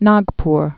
(nägpr)